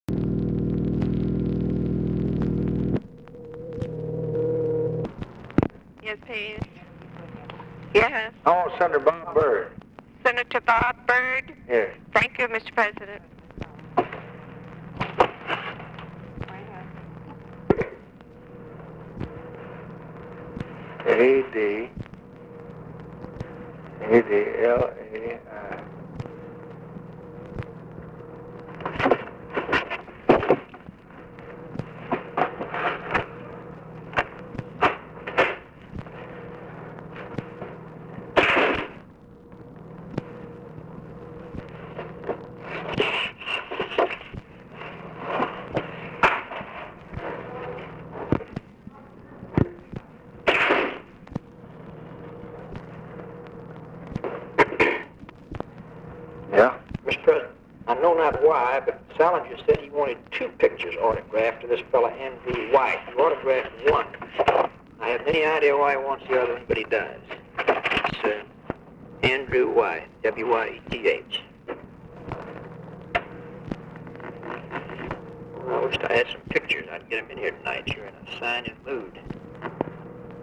LBJ ASKS TELEPHONE OPERATOR TO PLACE CALL TO ROBERT BYRD; OFFICE CONVERSATION ABOUT SIGNING PHOTO; LBJ TALKS WITH REUTHER ABOUT MICHIGAN POLITICS, STATE OF THE UNION MESSAGE, POVERTY, EDUCATION, MILITARY, HOBART TAYLOR, JR., CIVIL RIGHTS; TAX BILL
Secret White House Tapes